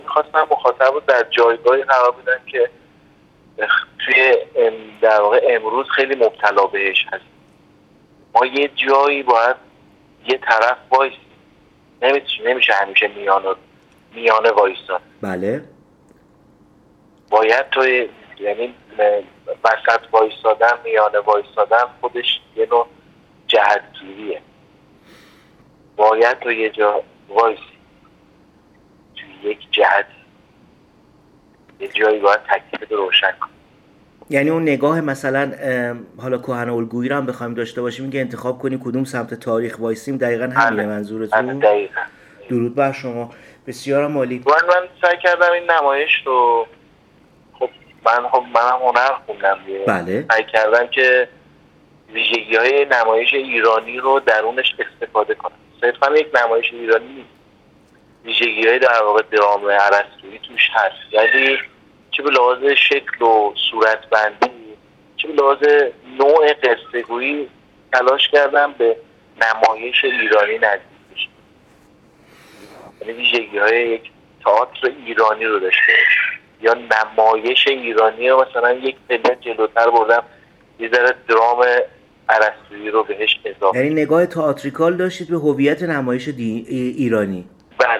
گفت‌گو